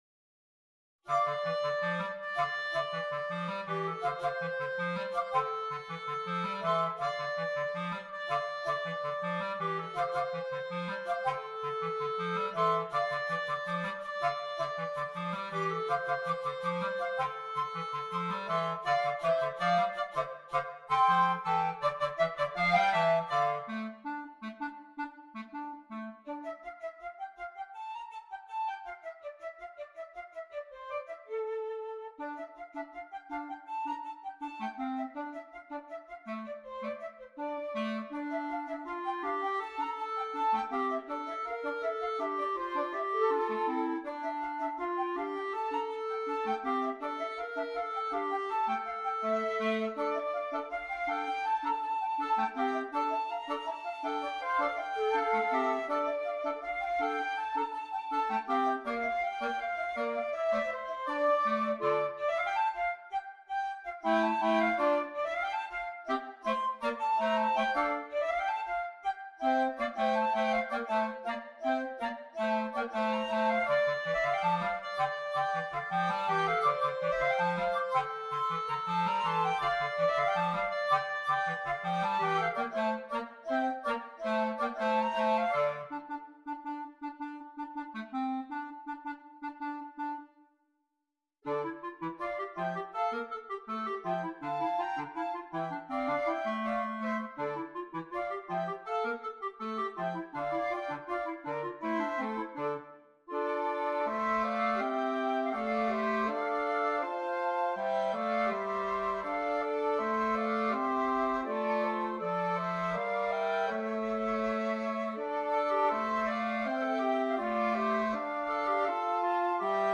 Voicing: 2 Flute and 2 Clarinet